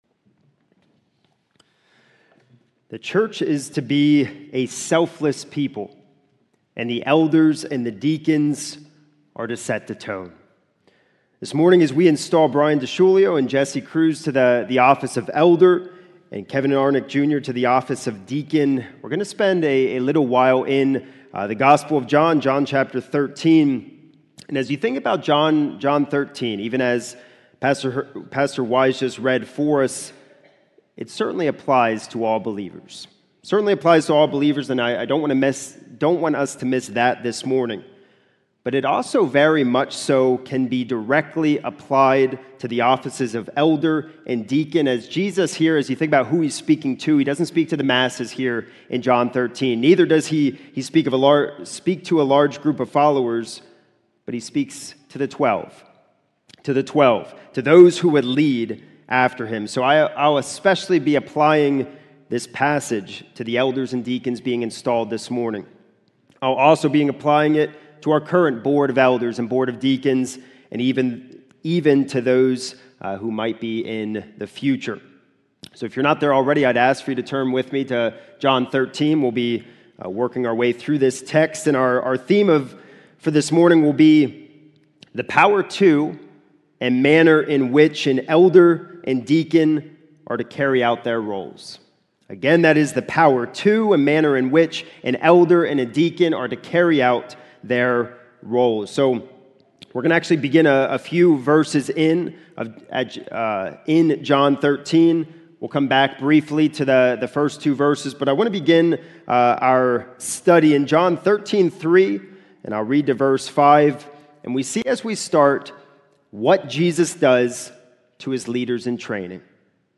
Elder and Deacon Installation Sermon – John 13:1-20 (AM) | Lebanon Bible Fellowship Church